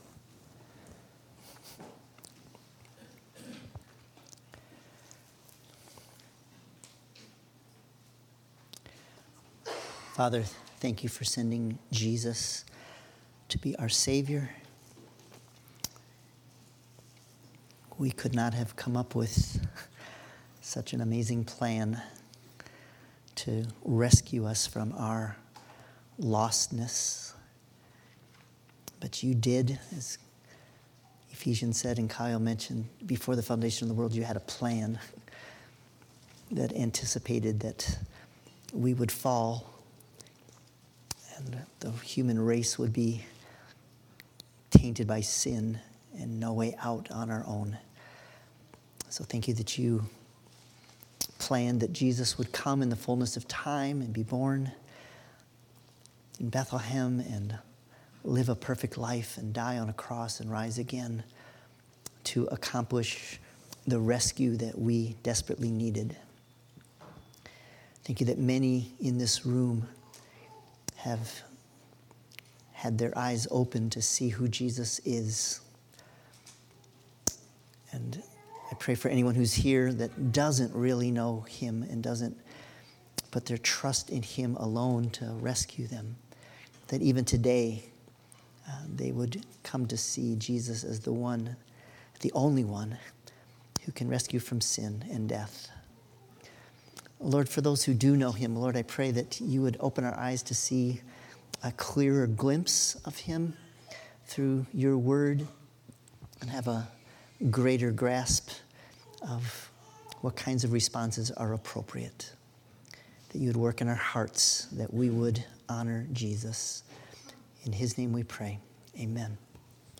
12-8-24-sermon.mp3